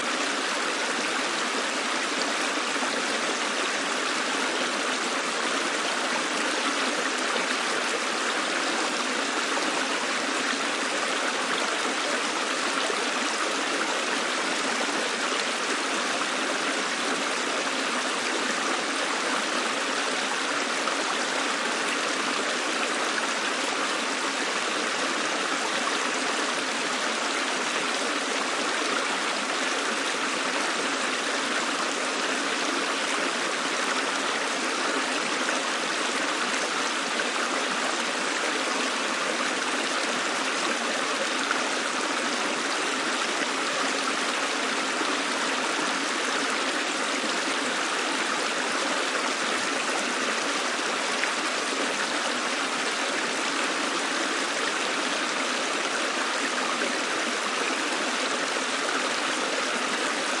描述：西班牙Aracena附近一条小溪的不同视角。麦克风设置在水流中间，有一个小三角架。M/S立体声
Tag: 场记录 性质 飞溅 冬季